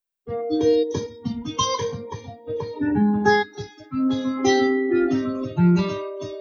I have just installed the latest version of Audacity and am getting poor results recording music.
I am just recording live from youtube with a good quality condenser mic (ART C-2).
You’ve got the speakers, microphone, and room acoustics, all affecting the sound.